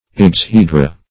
exhedra - definition of exhedra - synonyms, pronunciation, spelling from Free Dictionary Search Result for " exhedra" : The Collaborative International Dictionary of English v.0.48: Exhedra \Ex"he*dra\, n. [NL.]
exhedra.mp3